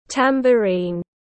Cái trống lục lạc tiếng anh gọi là tambourine, phiên âm tiếng anh đọc là /ˌtæm.bəˈriːn/
Tambourine /ˌtæm.bəˈriːn/